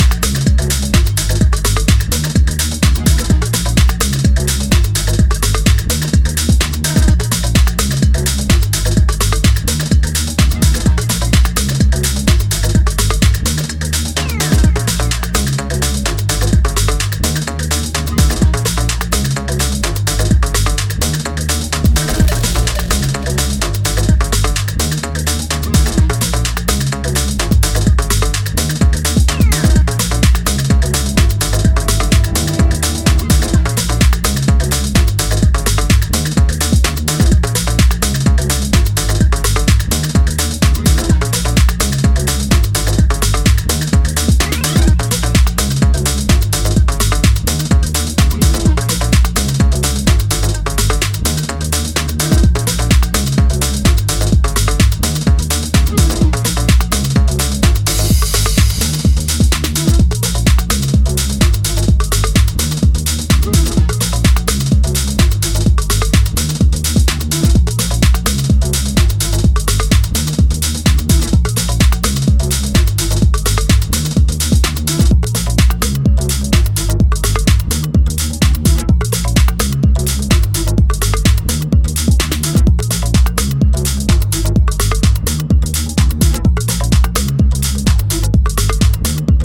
extending the trip with a darker bounce and a touch of acid.